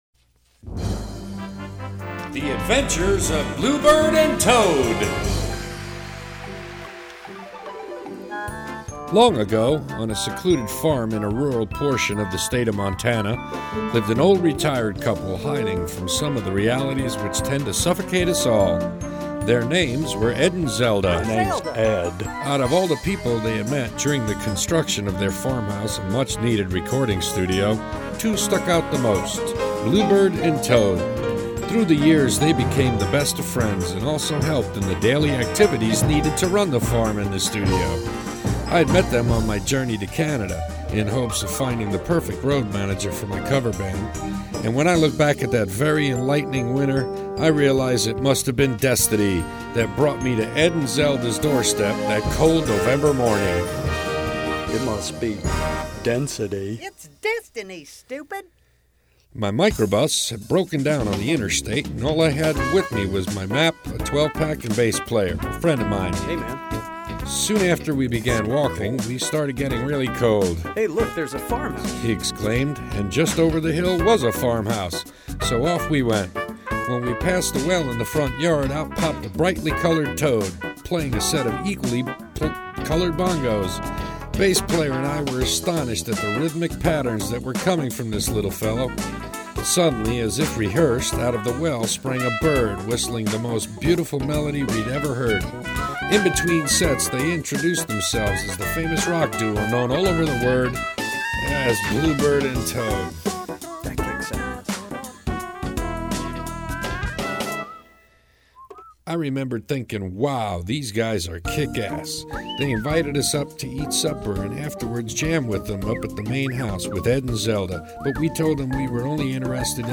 What an outstanding animated story of a musician’s life.
The audio animation is outstanding.